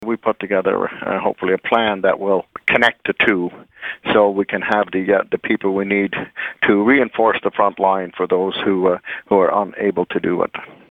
He tells Quinte News there are important jobs that need to be filled in the agri-food sector.